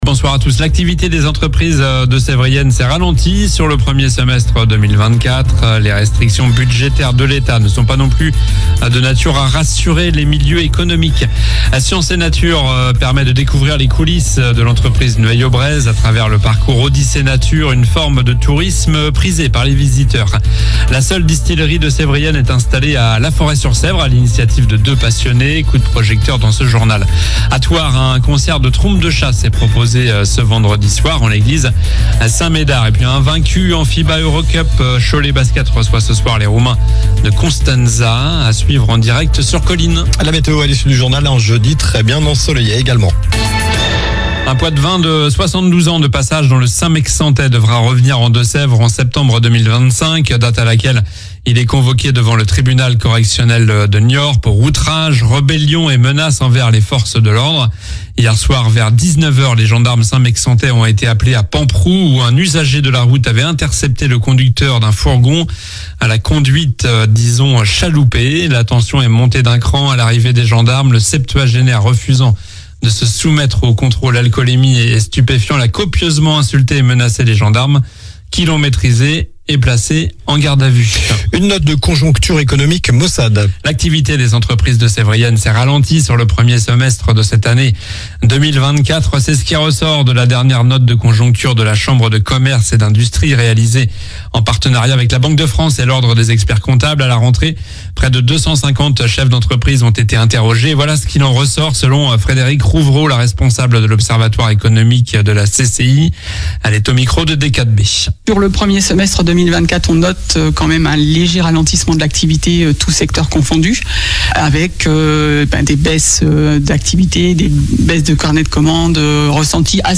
COLLINES LA RADIO : Réécoutez les flash infos et les différentes chroniques de votre radio⬦
Journal du mercredi 23 octobre (soir)